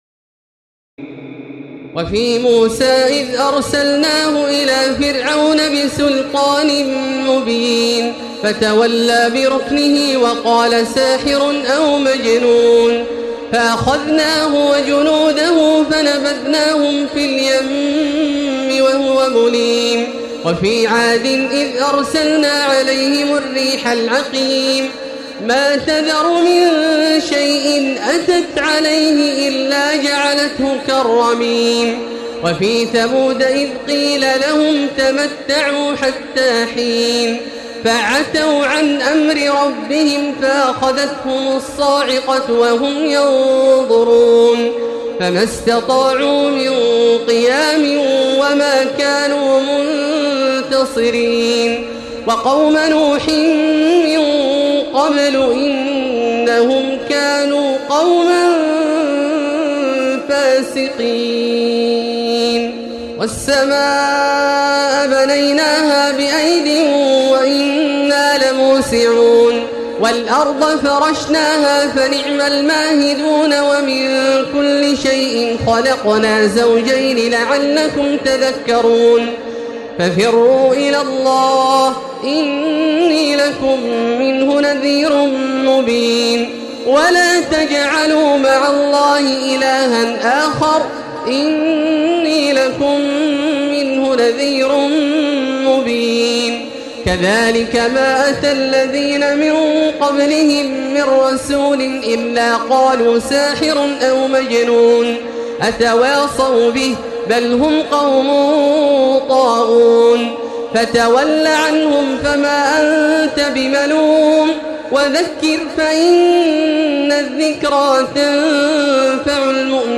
تراويح ليلة 26 رمضان 1436هـ من سور الذاريات(38-60) و الطور و النجم و القمر Taraweeh 26 st night Ramadan 1436H from Surah Adh-Dhaariyat and At-Tur and An-Najm and Al-Qamar > تراويح الحرم المكي عام 1436 🕋 > التراويح - تلاوات الحرمين